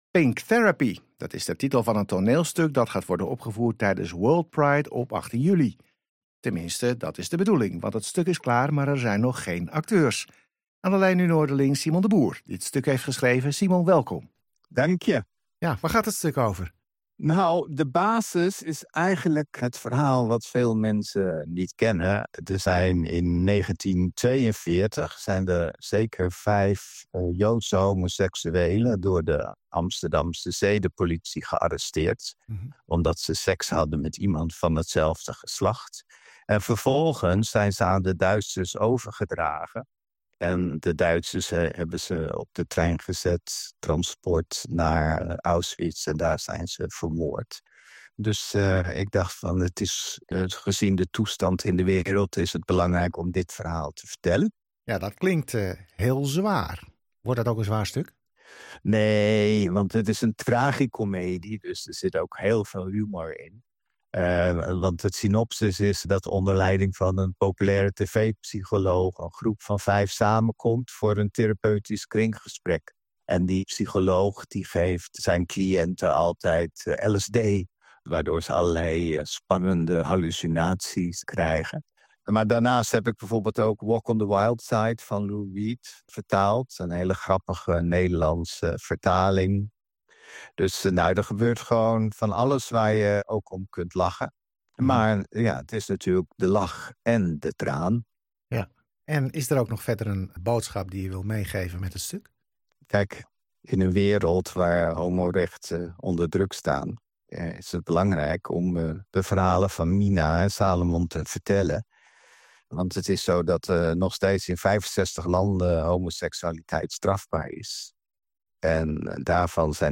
Luister hier naar het interview over de voorstelling met Radio Boven IJ